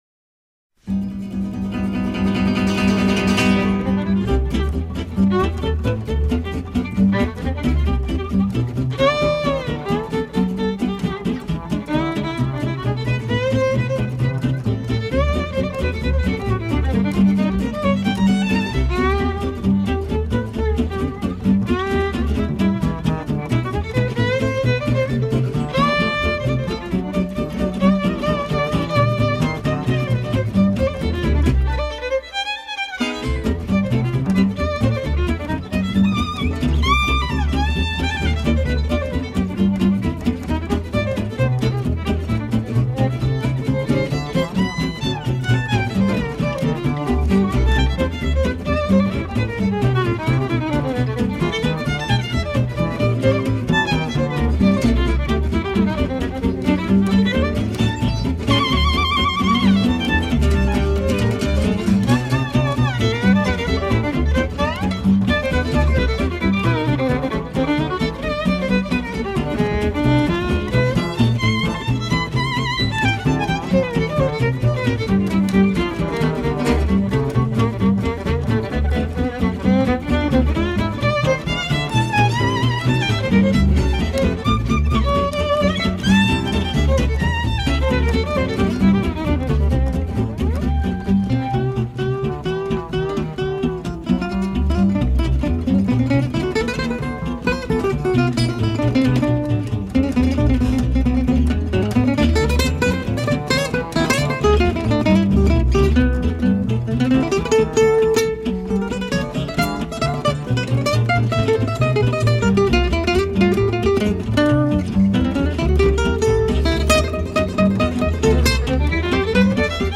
jazz standard